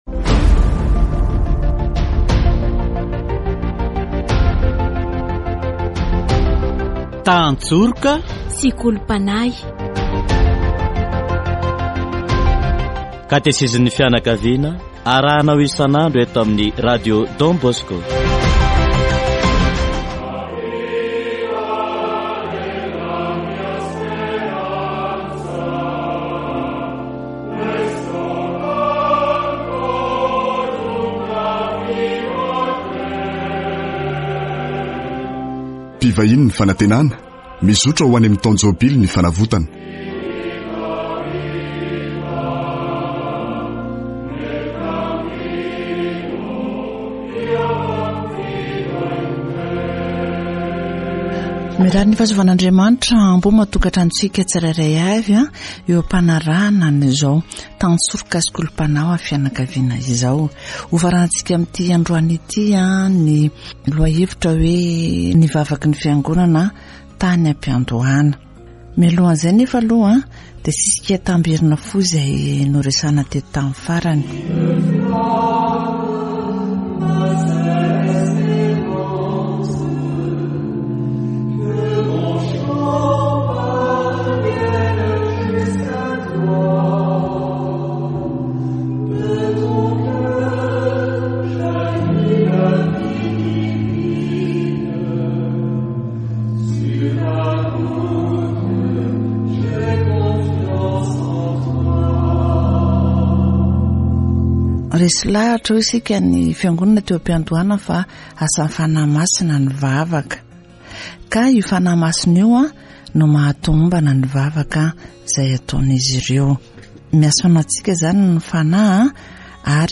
Katesizy momba ny vavaky ny fiangonana, tany am-piandohana